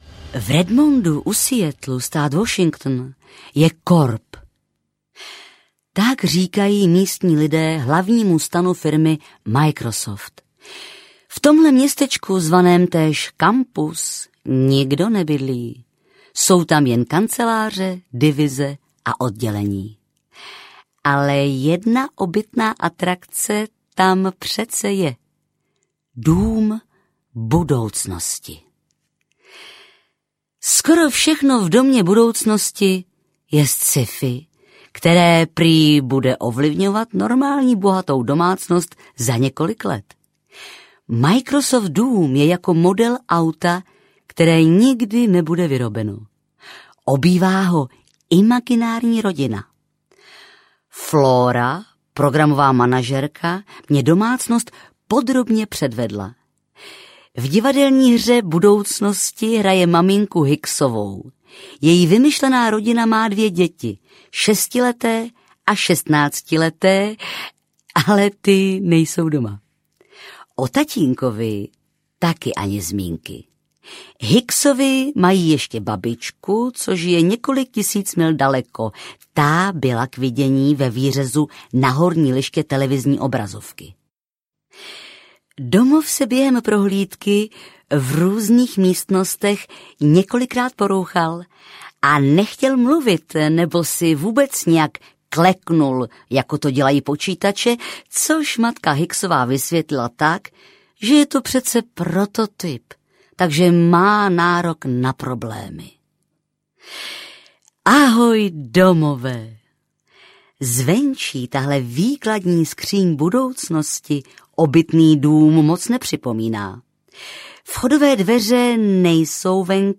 Ukázka z knihy
• InterpretBarbora Hrzánová